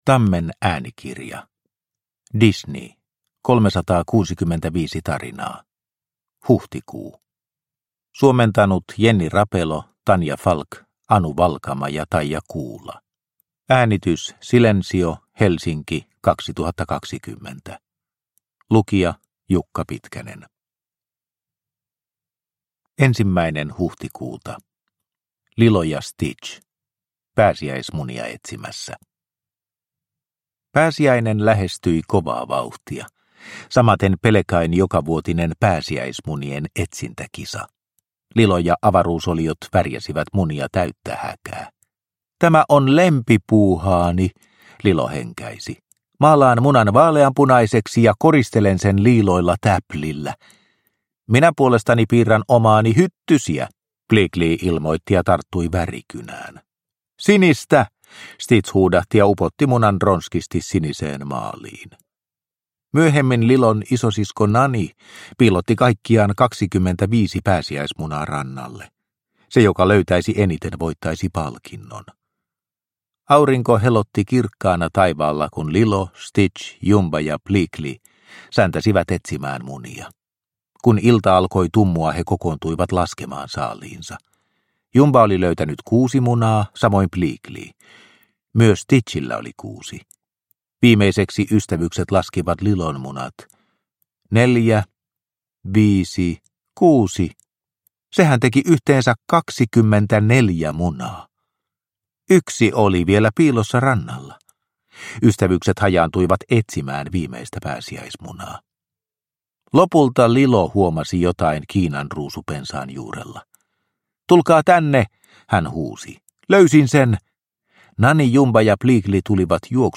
Disney 365 tarinaa, Huhtikuu – Ljudbok – Laddas ner